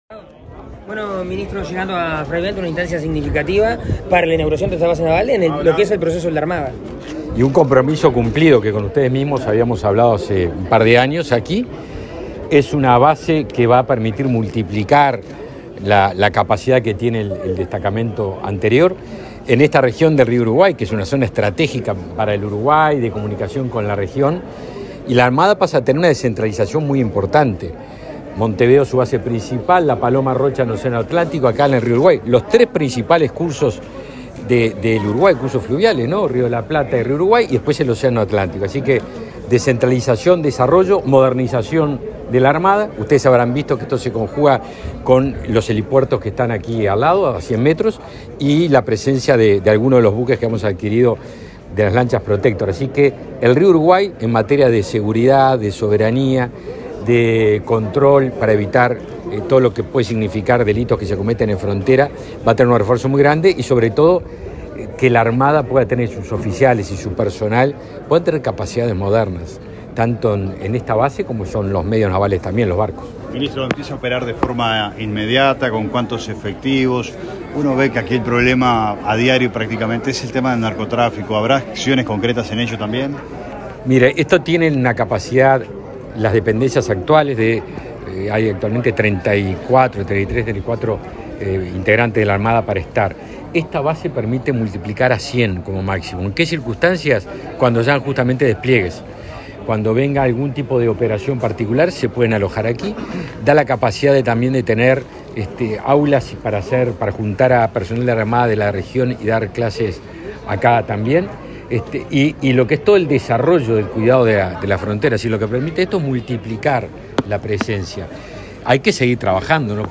Declaraciones del ministro de Defensa Nacional, Javier García
Luego, dialogó con la prensa.